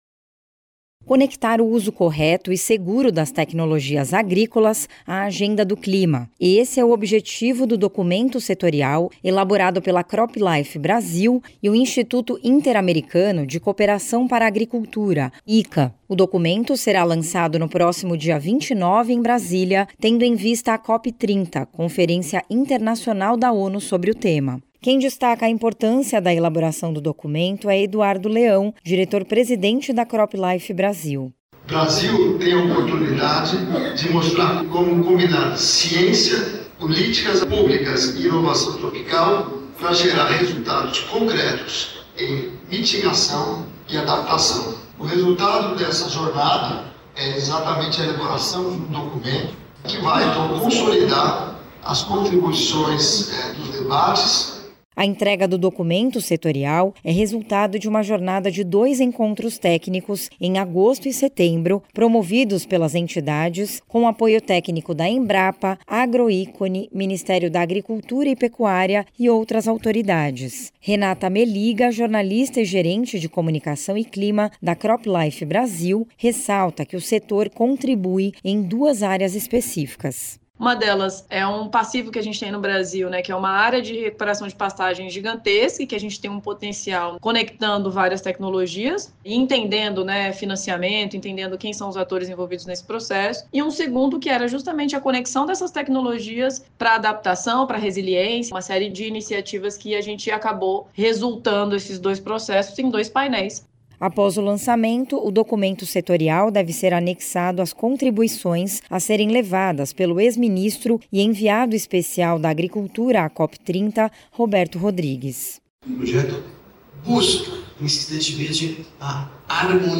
[Rádio] Setor de tecnologias agrícolas se prepara para a COP30 - CropLife